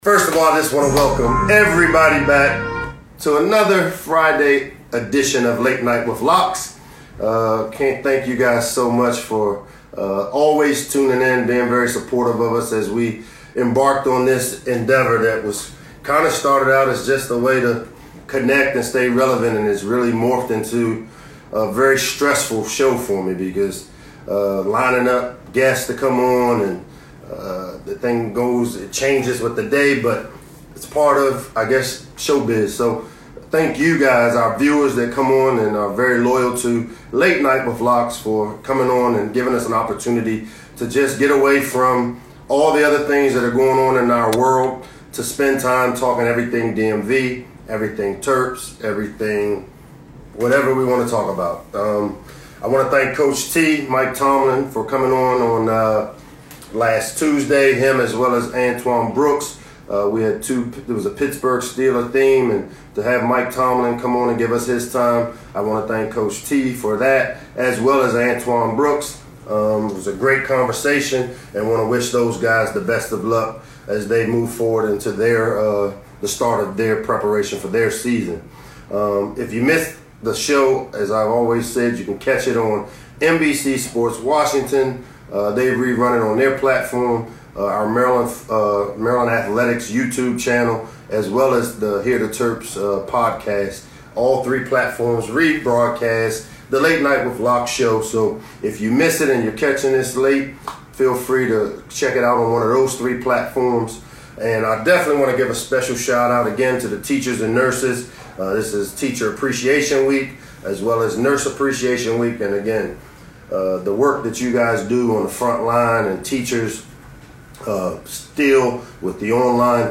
May 10, 2020 Late Night with Locks is an Instagram live show hosted by head football coach Michael Locksley every Tuesday and Friday evening at 7 p.m. This show featured Dwayne Haskins Jr. of the Washington Redskins and tennis star Frances Tiafoe.